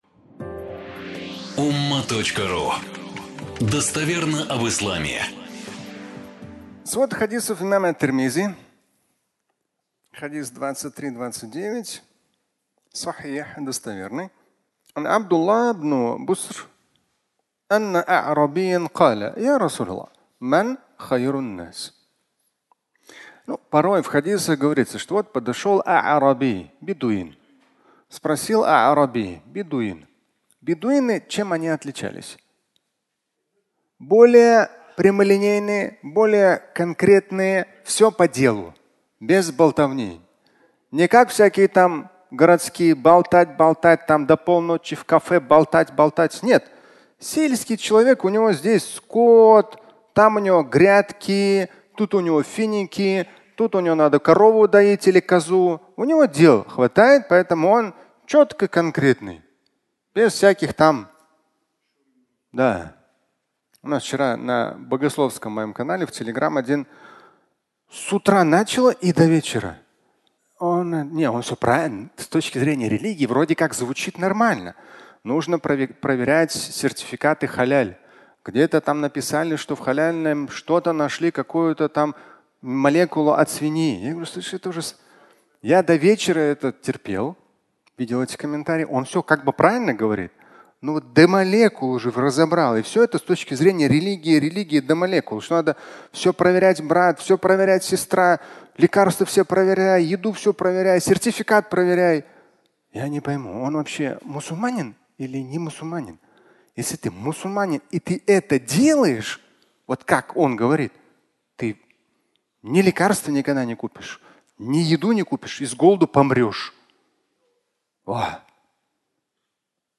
Кто лучший? Ч. 1 (аудиолекция)